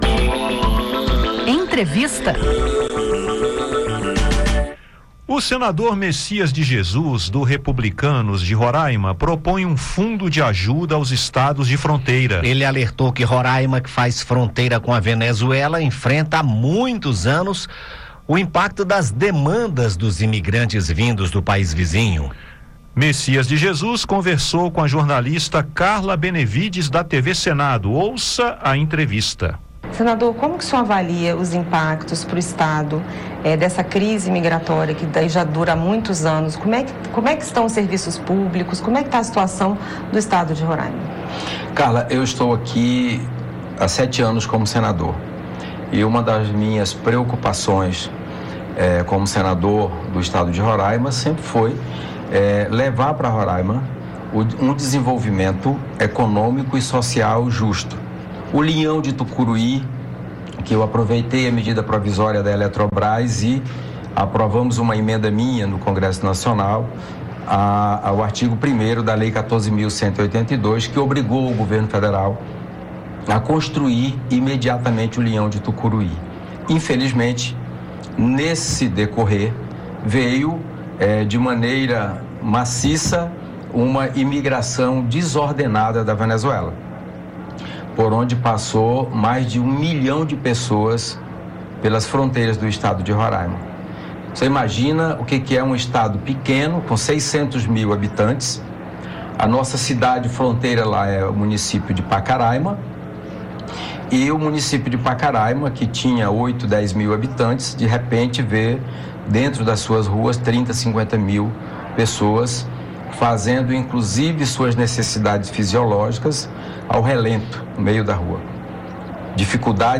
O senador Mecias de Jesus (Republicanos-RR) propõe um fundo de ajuda aos estados de fronteira. Em entrevista